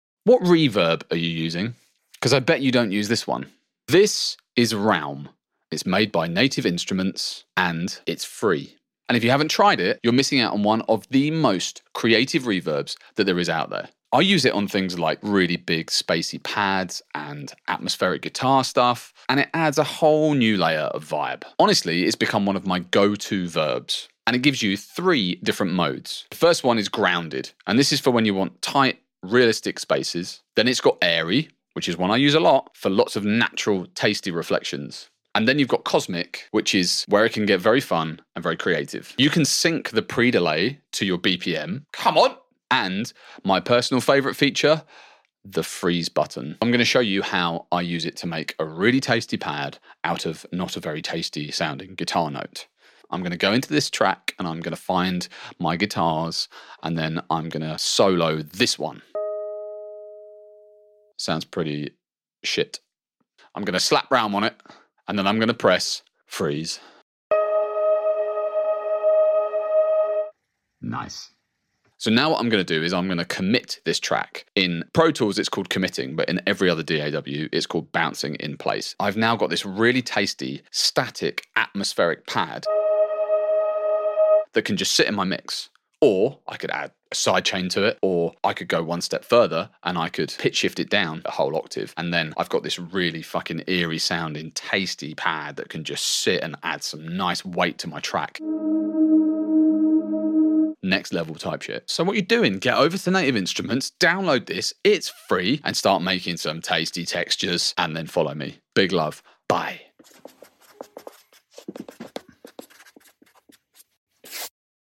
I use it on ambient guitars + pads all the time.